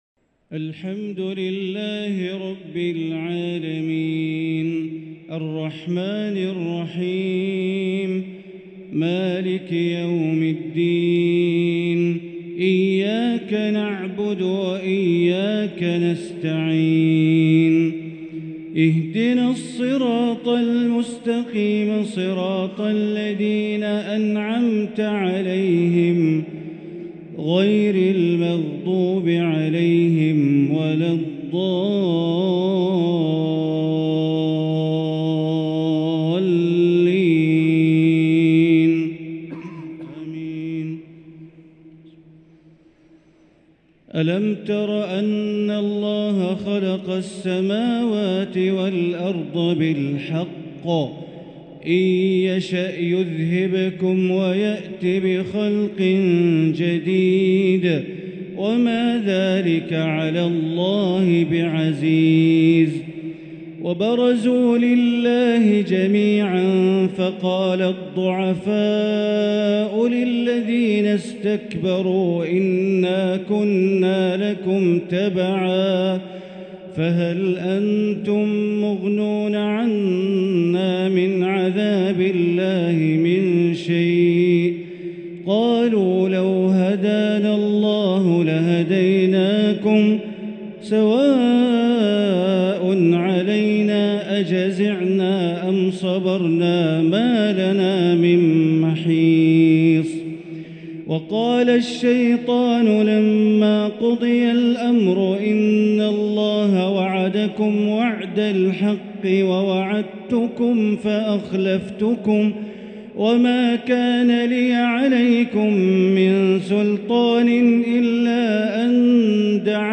تراويح ليلة 18 رمضان 1444هـ من سورتي إبراهيم (19-52) و الحجر كاملة | Taraweeh 18 th night Ramadan 1444H Surah Ibrahim and Al-Hijr > تراويح الحرم المكي عام 1444 🕋 > التراويح - تلاوات الحرمين